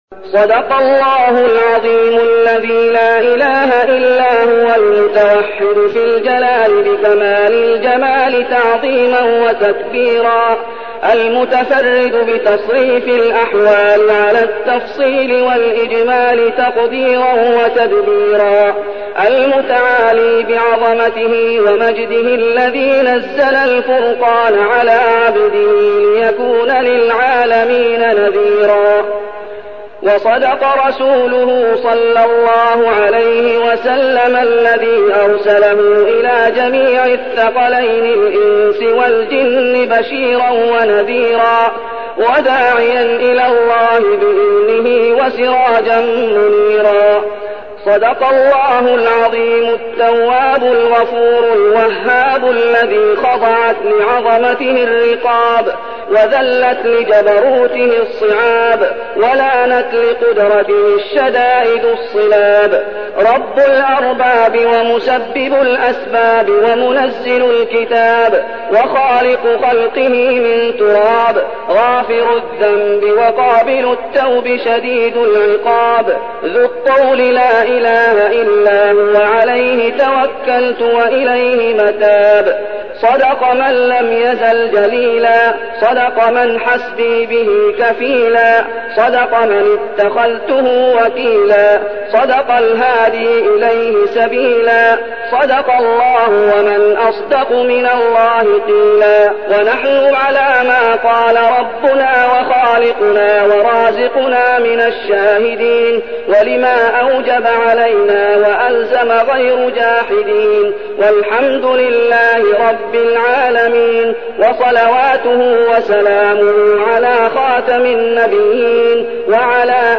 المكان: المسجد النبوي الشيخ: فضيلة الشيخ محمد أيوب فضيلة الشيخ محمد أيوب ختم القرآن